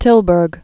(tĭlbûrg, -bœr)